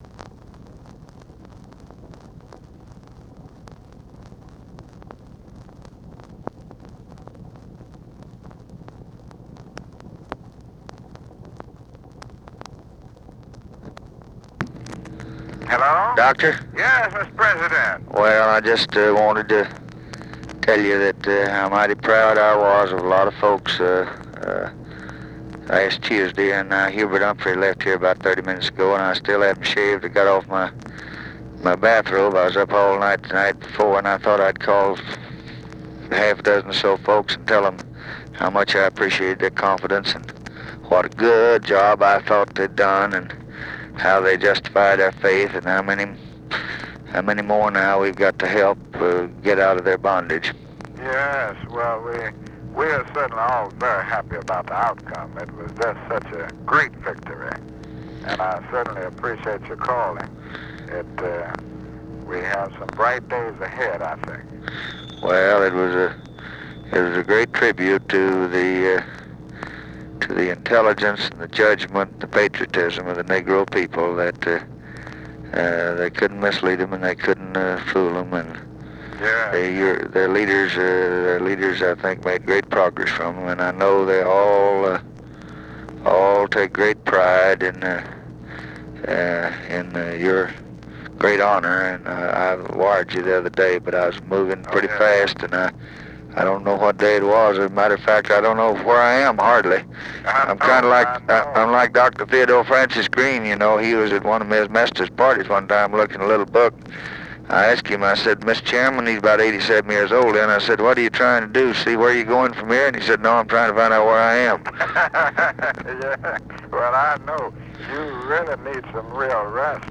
Conversation with MARTIN LUTHER KING, November 5, 1964
Secret White House Tapes